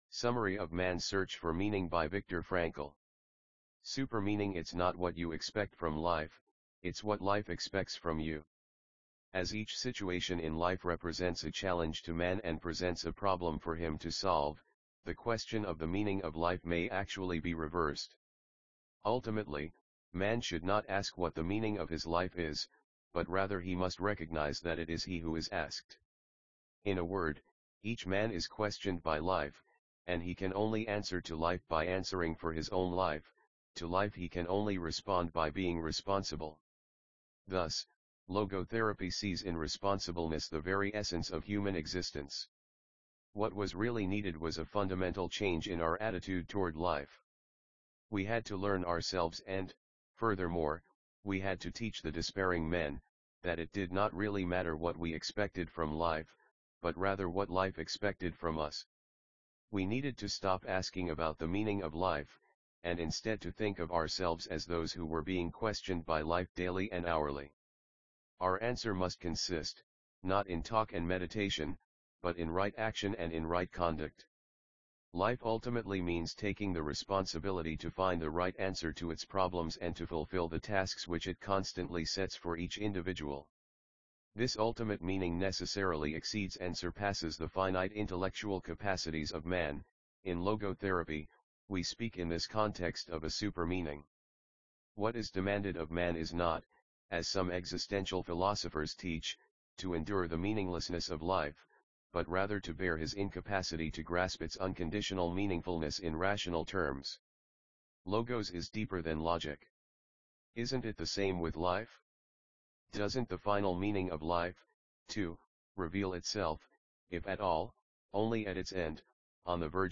Summary-Audio-of-Mans-Search-for-Meaning-by-Viktor-Frankl.mp3